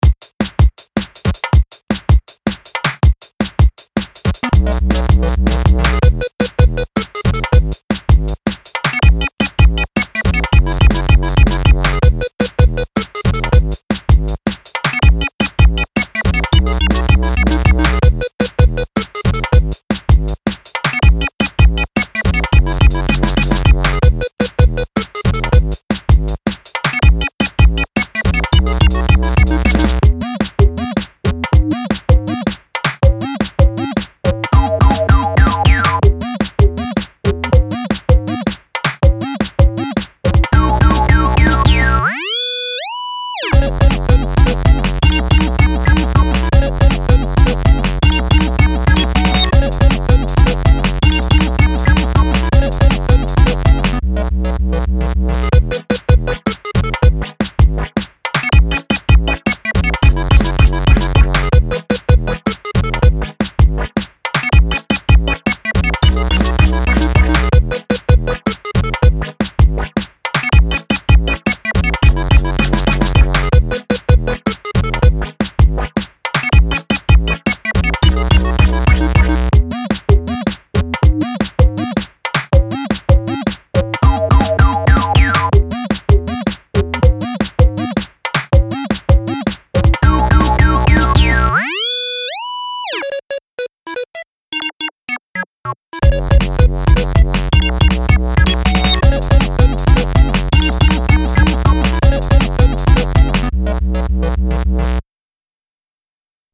Punchy uptempo quirky 8-bit drum'n'bass.